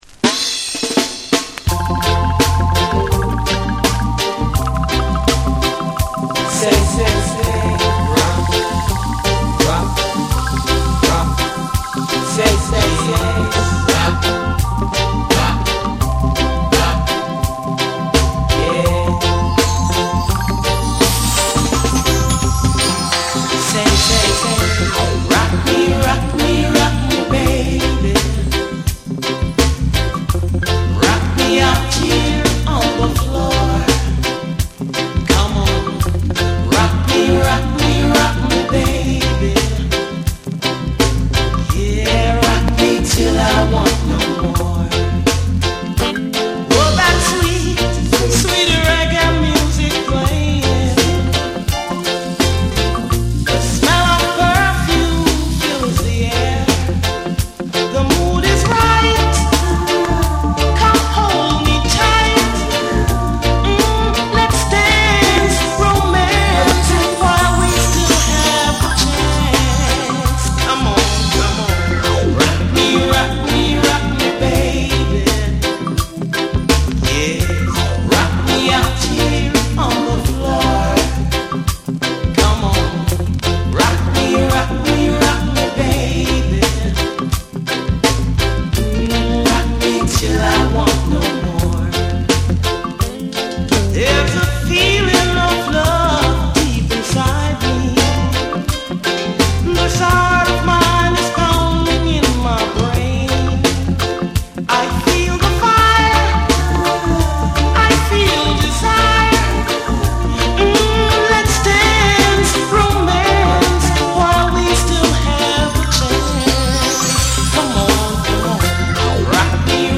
キャッチーでホッコリしたレゲエを聴かせてくれます！
REGGAE & DUB